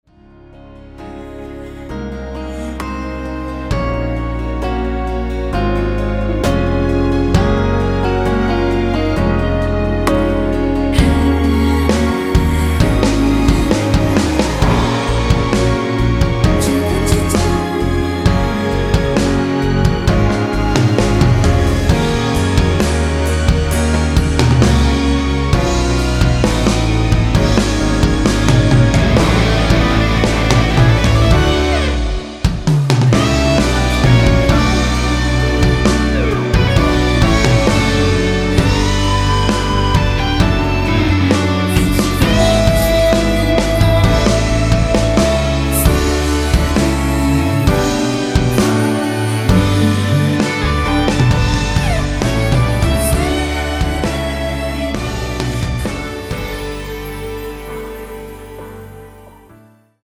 원키(1절앞+후렴)으로 진행되는 코러스 포함된 MR입니다.
Db
앞부분30초, 뒷부분30초씩 편집해서 올려 드리고 있습니다.
중간에 음이 끈어지고 다시 나오는 이유는